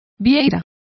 Complete with pronunciation of the translation of scallop.